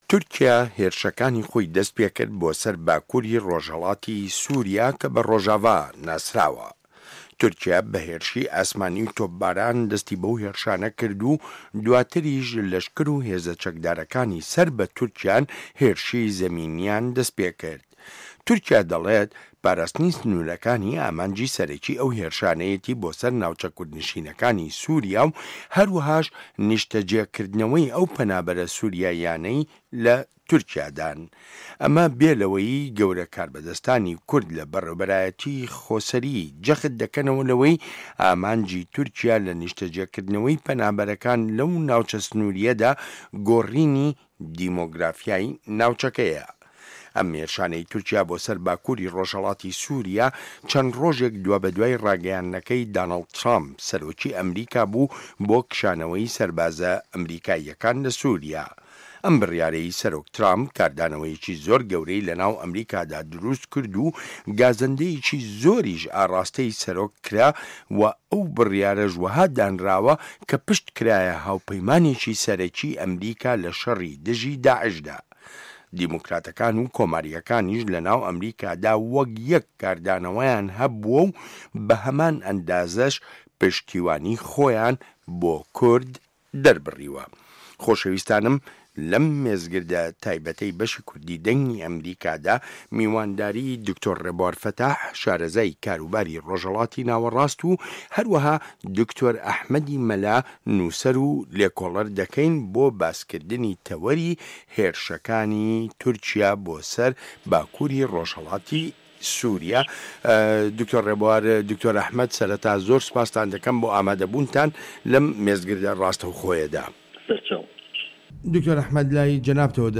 مێزگرد: کردە لەشکرییەکانی کانی ئاشتی لە پایزێکی پڕ لە کزەدا